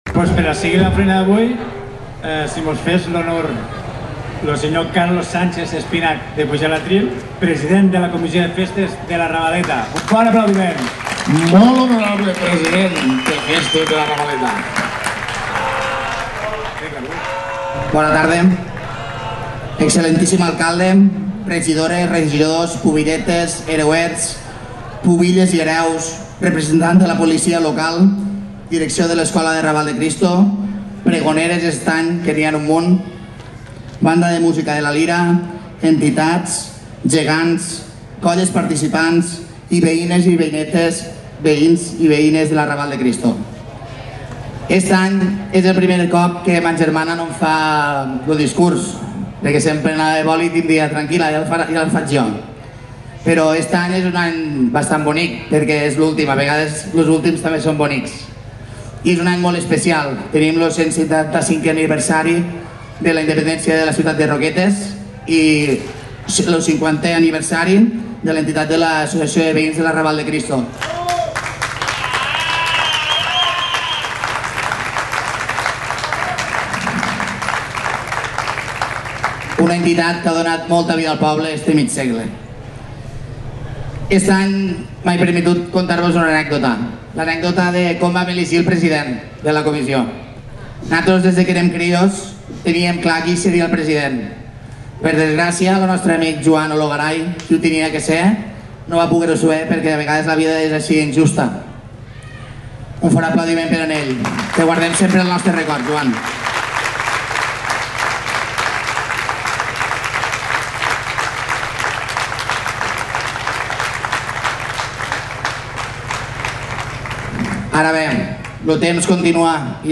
Prego-de-les-Festes-de-La-Raval-de-Cristo-2025-web.mp3